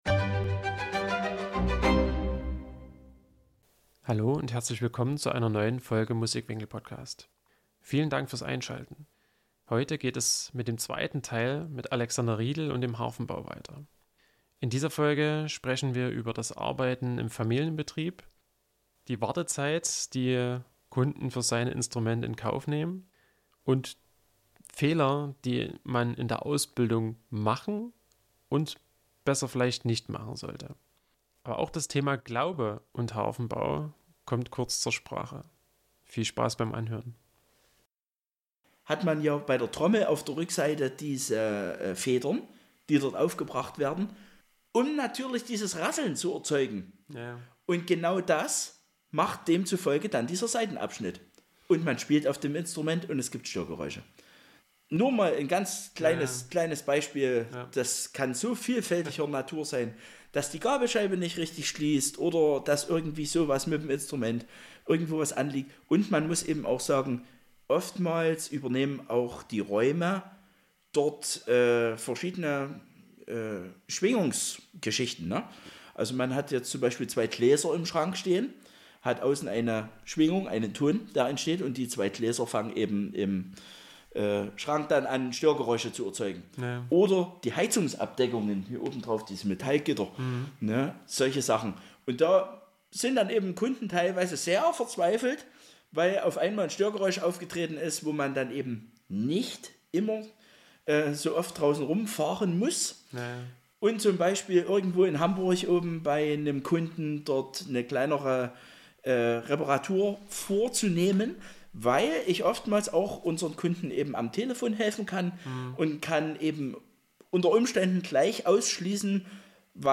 Dabei erzählt er von Fehlern die er früher schon machen musste und solchen, die man auch heute unbedingt vermeiden sollte. Neben dem tollen Gefühl ein neues Instrument an Kunden auszuliefern, sprechen wir aber auch über die Herausforderungen seines Berufs.